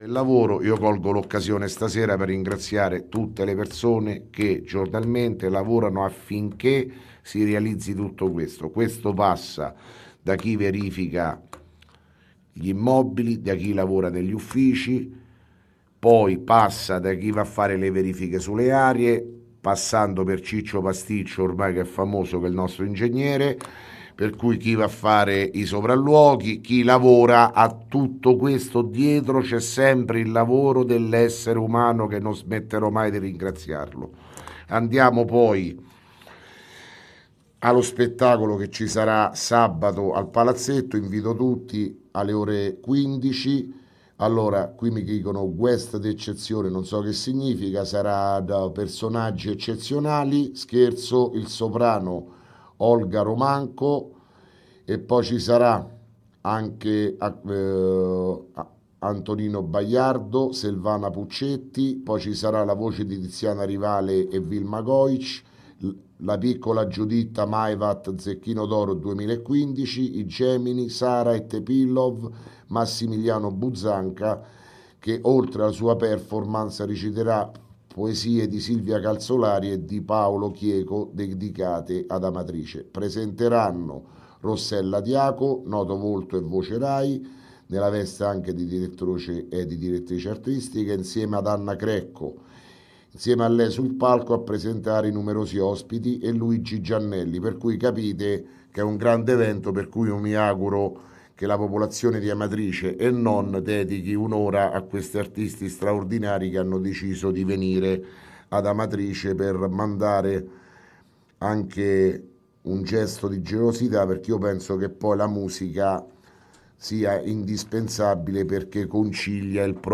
Di seguito il messaggio audio del Sindaco Sergio Pirozzi del 16 marzo 2017